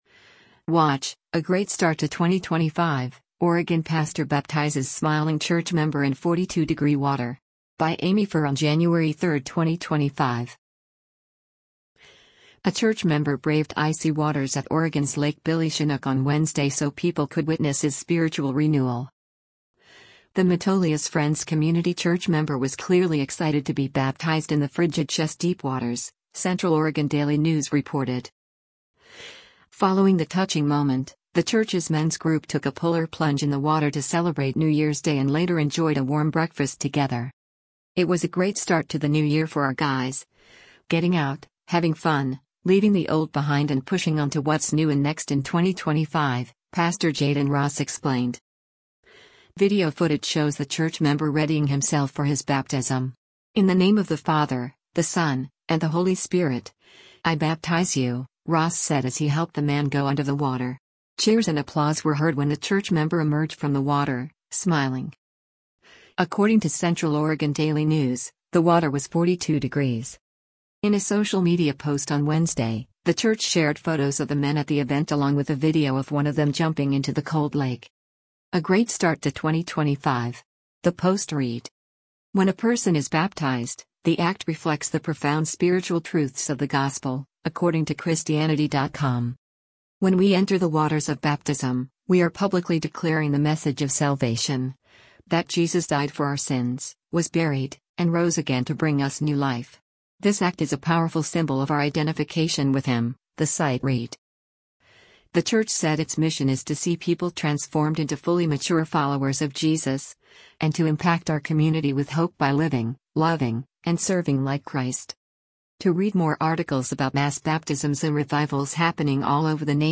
A church member braved icy waters at Oregon’s Lake Billy Chinook on Wednesday so people could witness his spiritual renewal.
Cheers and applause were heard when the church member emerged from the water, smiling.